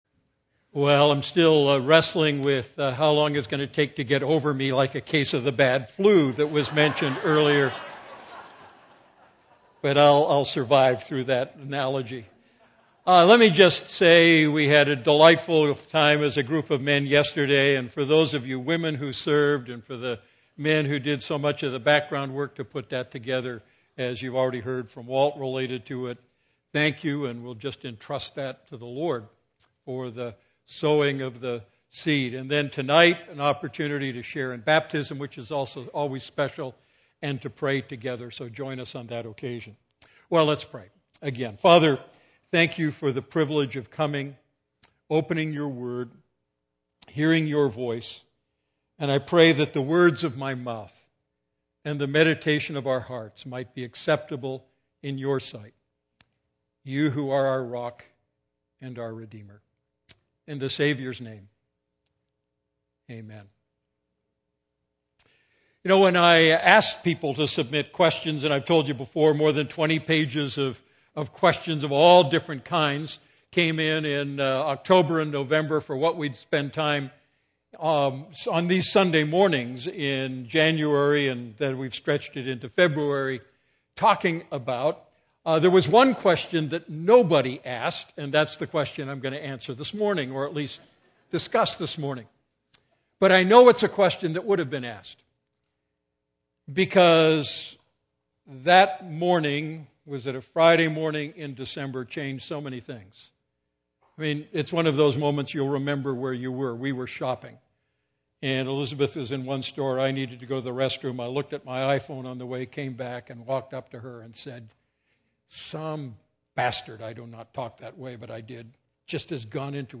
A message from the series "Great Questions?."